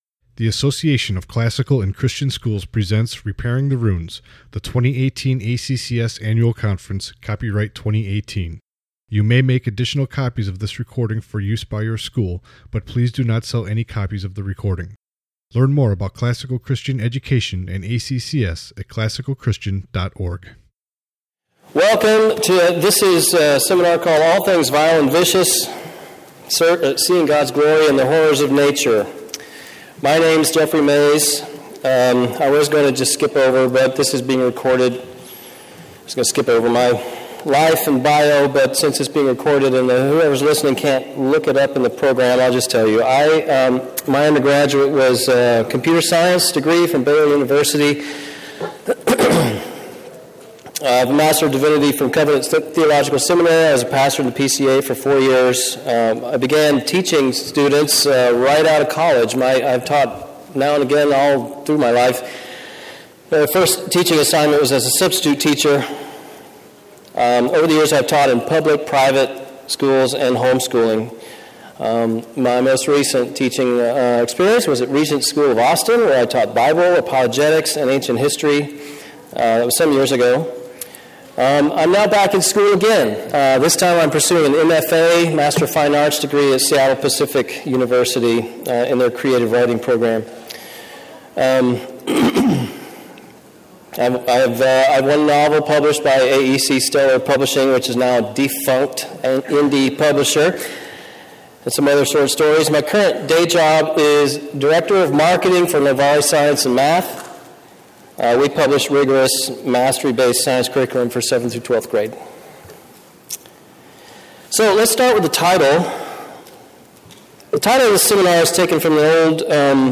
2018 Workshop Talk | 50:04 | All Grade Levels, Science
Additional Materials The Association of Classical & Christian Schools presents Repairing the Ruins, the ACCS annual conference, copyright ACCS.